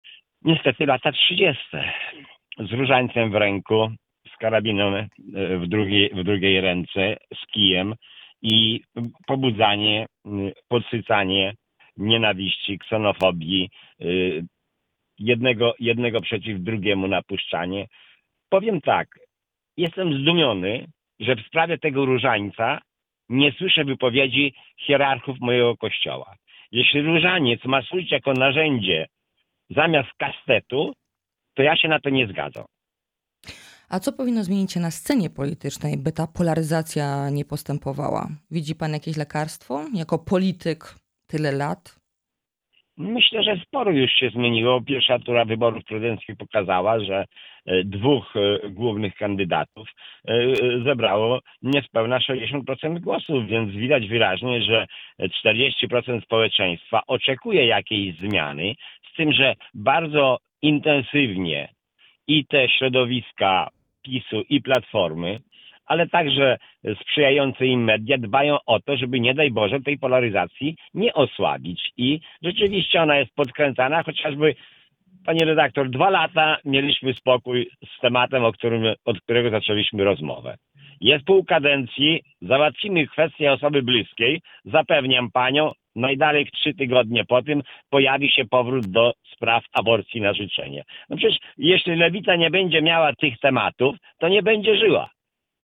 W dalszej części rozmowy pytaliśmy o wulgarny i przemocy język wśród polityków.